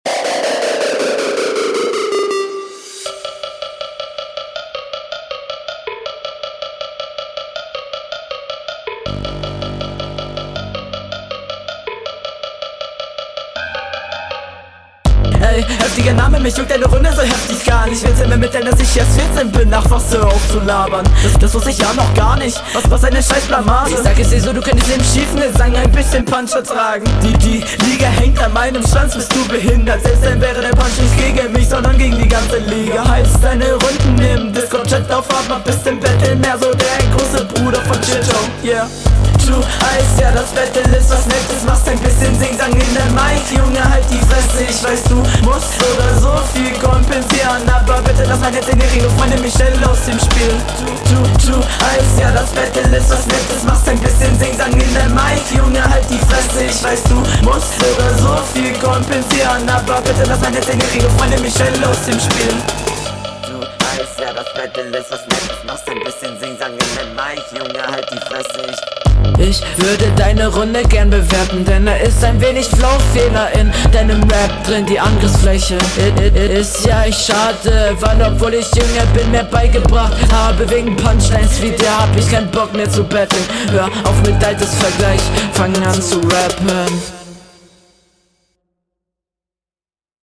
Soundqualität: Direkt viel besser abgemischt als dein Gegner und dadurch wirkt deine Stimme wesentlich passender …